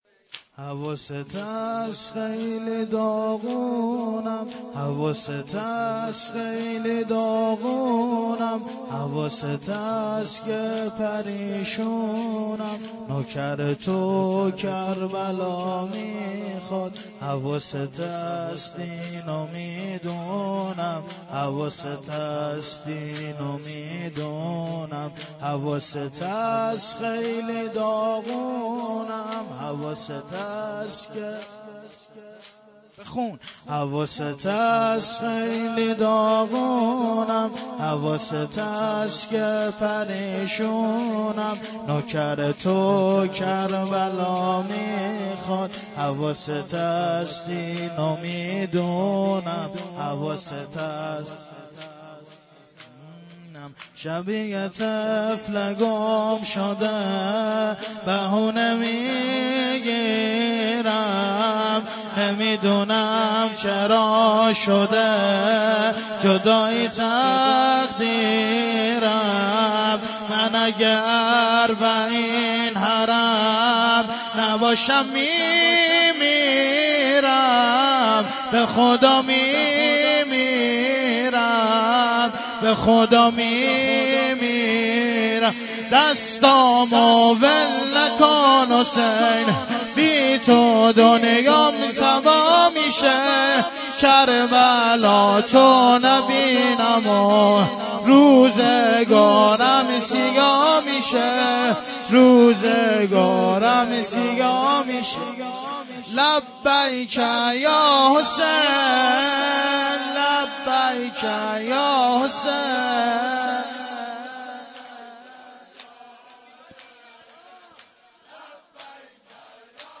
شهادت امام جعفرصادق(ع) ـ ۱۴۳۹ ـ جمعه ۱۵ تیر ۱۳۹۷